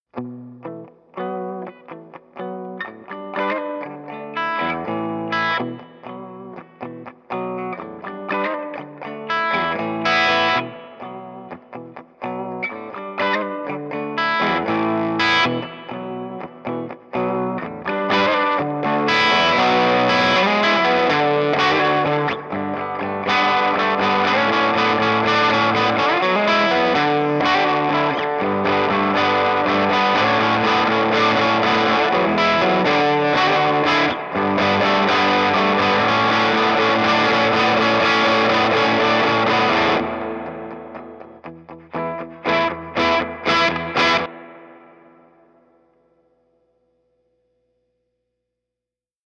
CLASS A 5W - TUBE REVERB - 12" Speaker - 6V6/6L6/EL-34 Compatible
SPACE TONE REVERB SAMPLES
As always, no effects or pedals were employed; you're only hearing the ST-Reverb via an SM-57.
INSTRUMENTS
57 Les Paul Goldtop Reissue
83 Gibson 335
Mic: Shure SM57
Pre-amp: Universal Solo/610
space_tone_reverb_1_256kbps.mp3